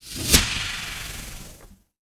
WHOOSHES
WHOOSH_Steam_Fast_03_mono.wav